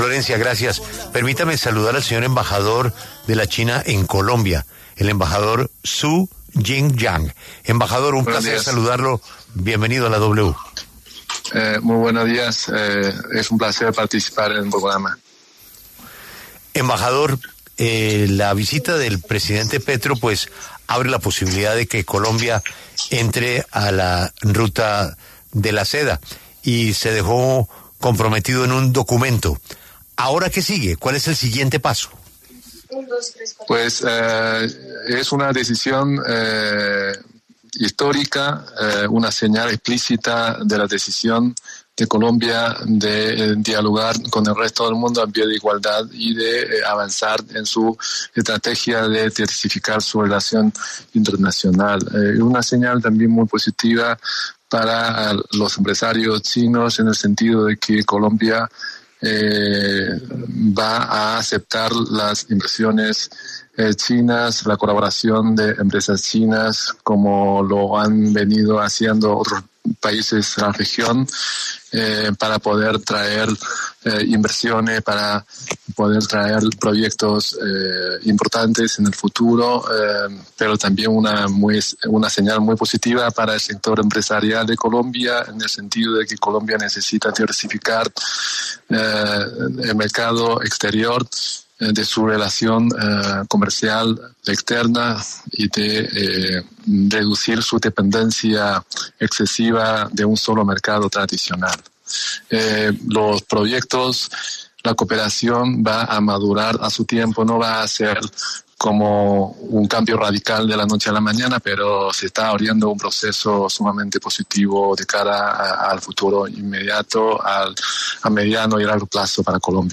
Zhu Jingyang, embajador de China en Colombia, aseguró en La W que la Ruta de la Seda traería proyectos importantes en un futuro.